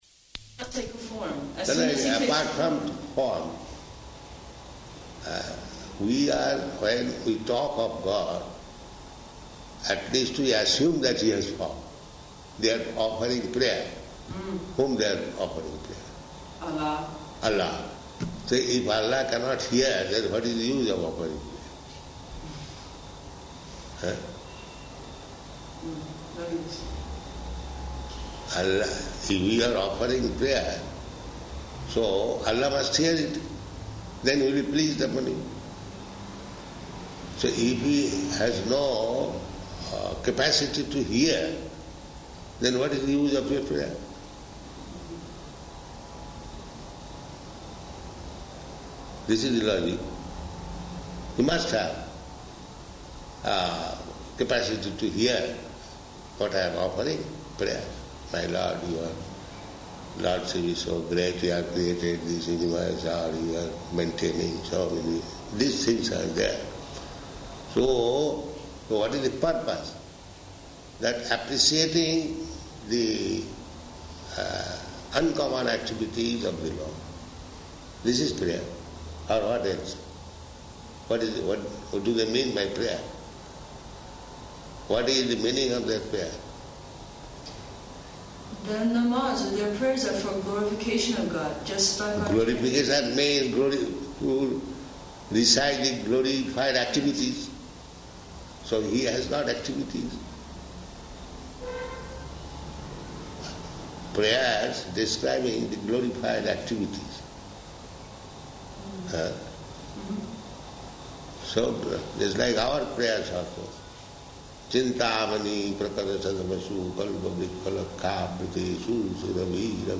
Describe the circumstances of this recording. -- Type: Conversation Dated: August 9th 1976 Location: Tehran Audio file